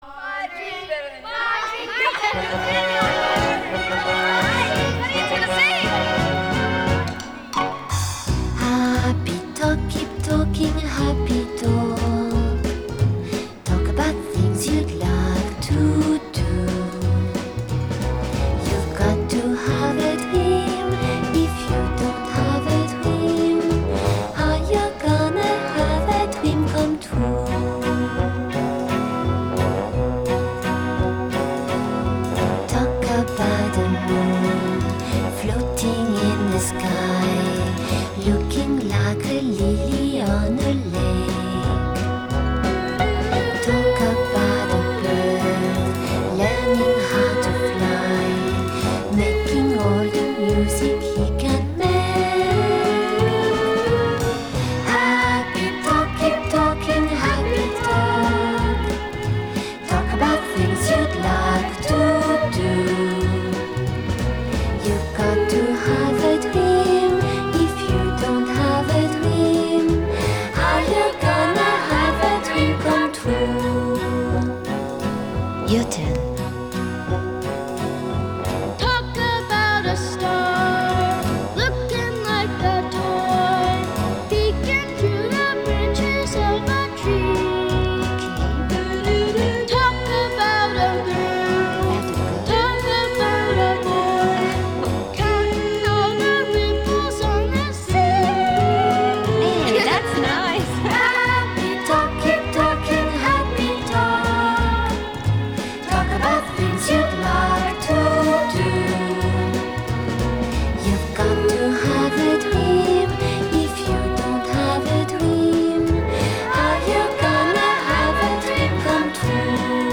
Genre: Pop, Vocal, Easy Listening